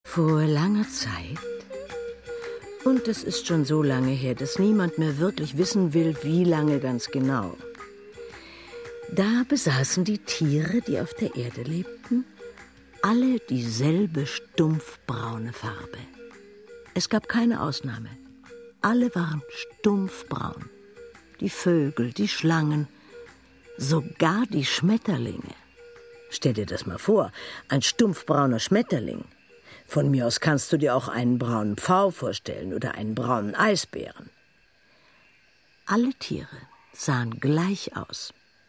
Wie die Tiere zu ihren Farben kamen: Ein aufwendig produziertes Hörspiel voller Magie, afrikanischen Klängen und Spaß am Geschichtenerzählen.
Passagen in den Sprachen Englisch, Zulu und Xosa, sind in die deutschen Versionen eingeblendet. Stimmen und Sprachen, die ausdrucksvollen Klick- und Zischlaute der Xosa, die Musik von Francis Bebey schaffen ein Hörstück von fremdem, magischem Reiz, das den Zauber und die Atmosphäre afrikanischer Erzählkunst intensiv vermittelt.